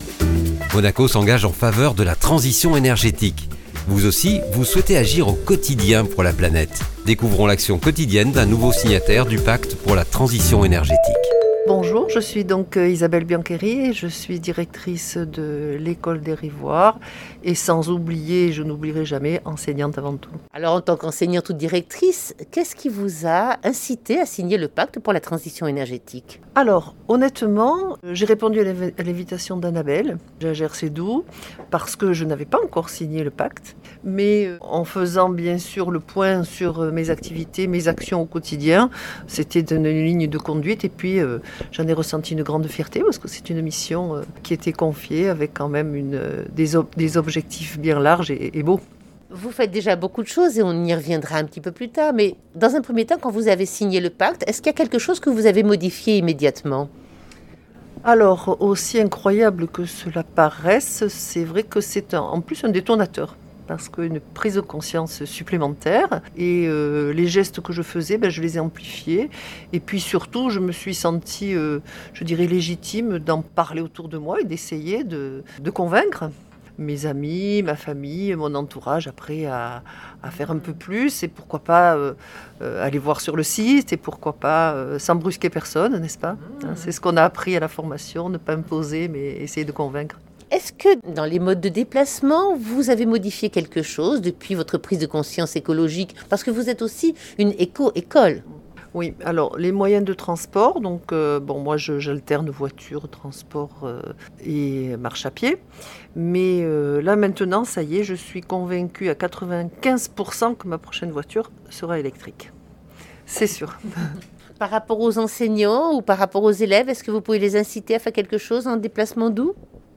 Interview d'une Ambassadrice hyper-motivée de la Mission pour la Transition Energétique qui entraine dans son sillage de nombreux signataires, grands et petits